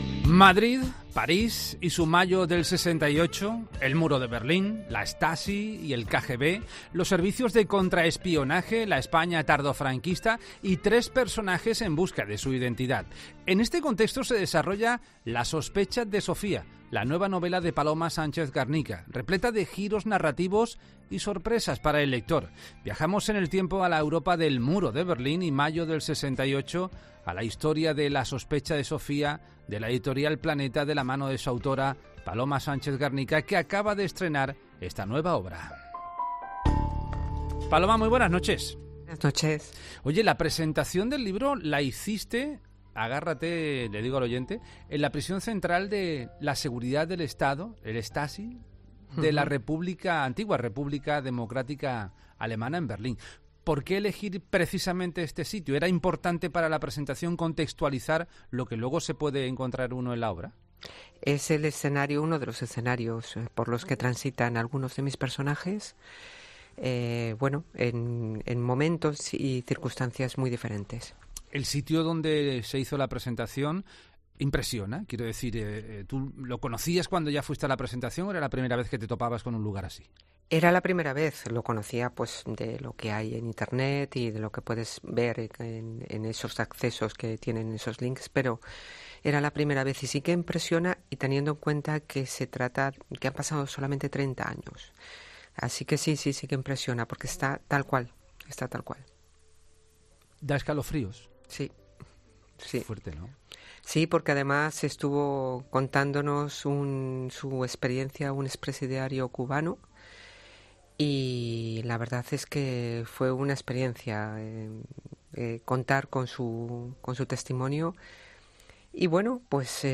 La escritora presenta su nueva novela 'La sospecha de Sofía' en 'La Noche de COPE'
Viajamos en el tiempo al Muro de Berlín y mayo del 68 y hablamos con la escritora.